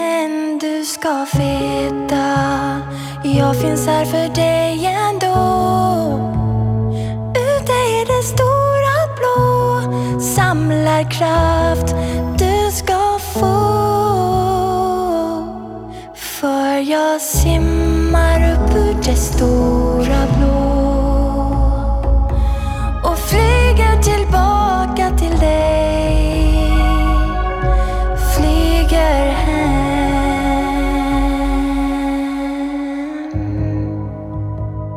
Pop
Жанр: Поп музыка